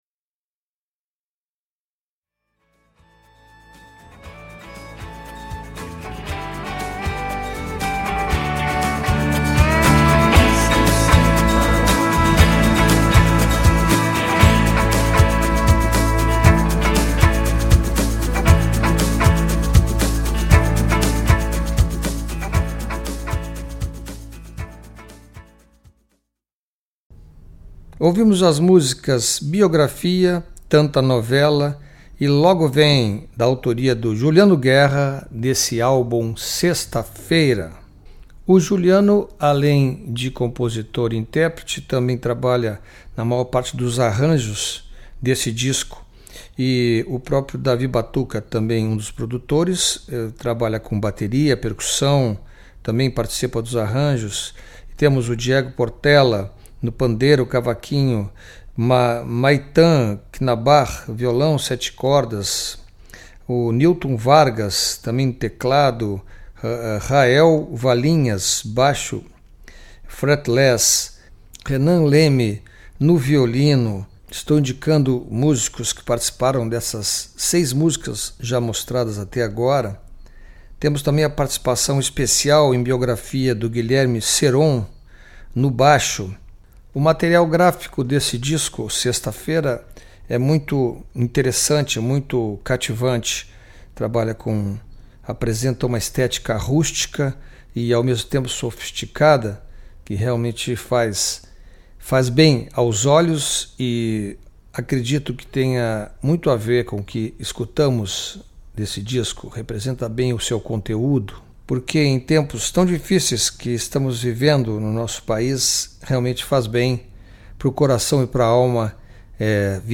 canções de tom sarcástico, mais carregadas instrumentalmente
com algumas singelas e algo parnasianas canções de amor